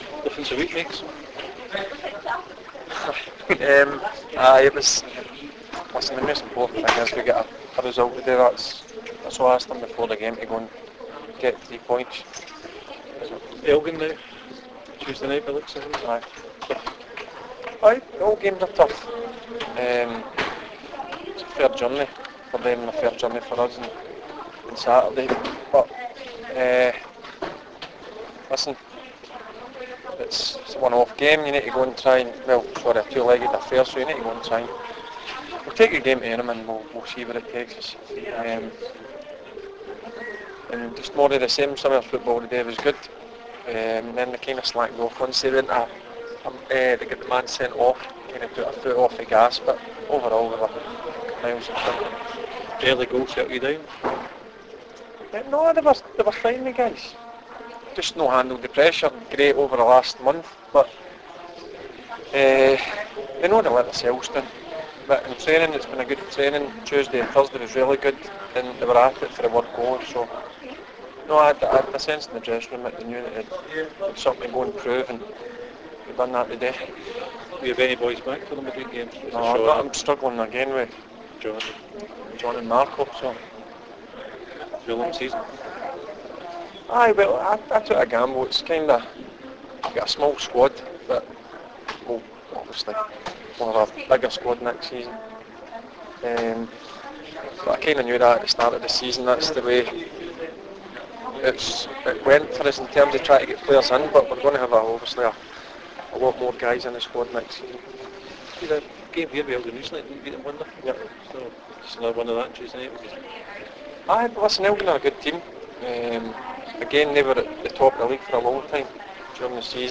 Barry Ferguson's press conference after the Ladbrokes League 2 match.